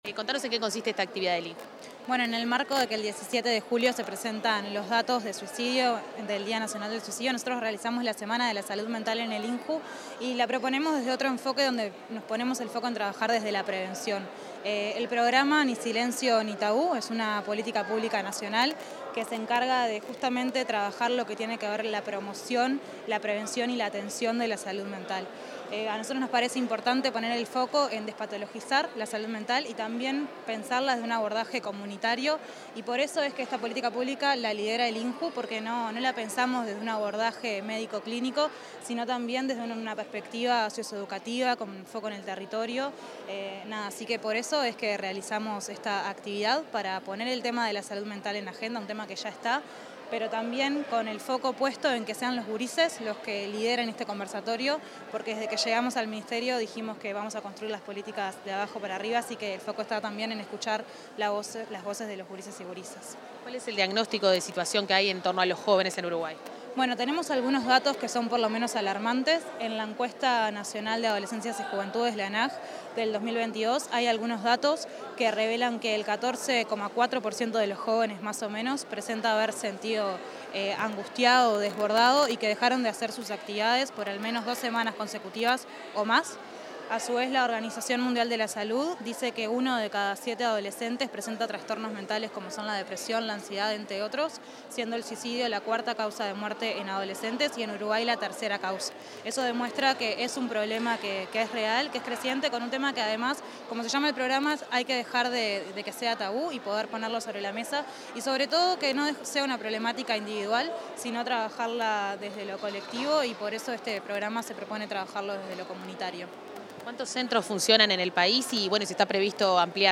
Declaraciones de la directora del INJU, Eugenia Godoy